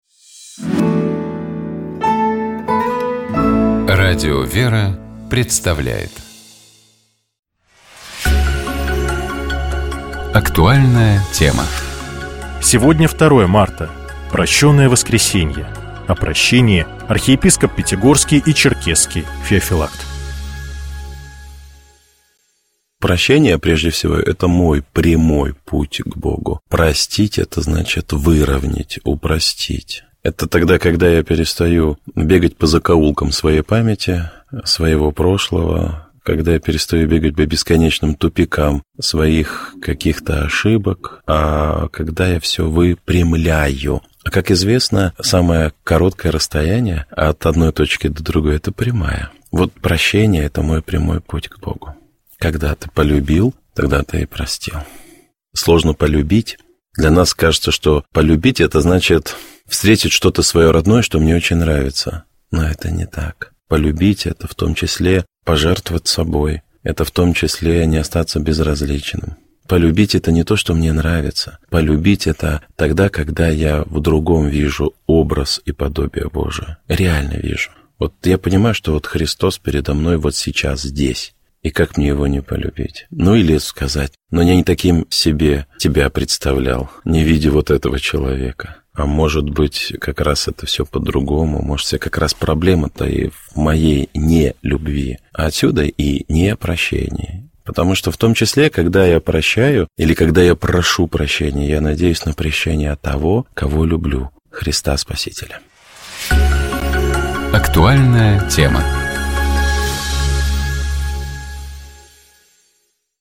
Гость программы — Владимир Легойда, председатель Синодального отдела по взаимоотношениям Церкви с обществом и СМИ, член Общественной палаты РФ.